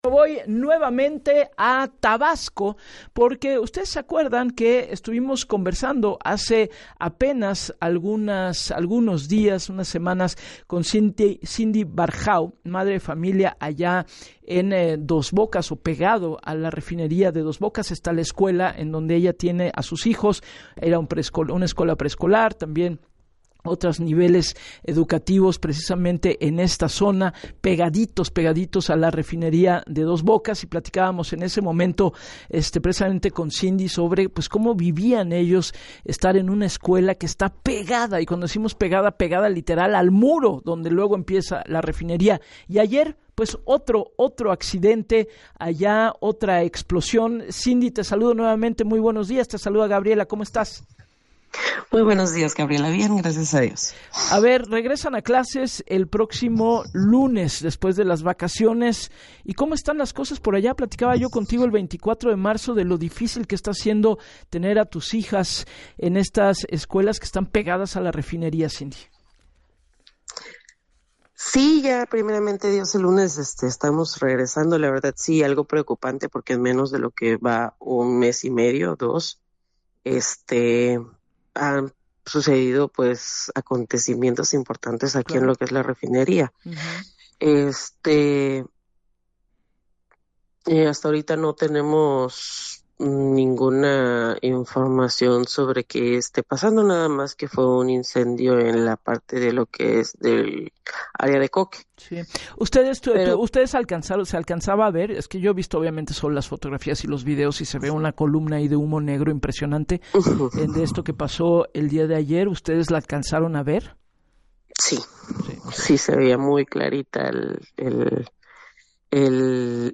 En entrevista para “Así las Cosas” con Gabriela Warkentin